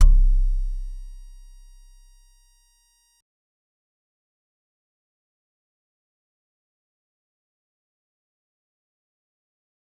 G_Musicbox-C1-pp.wav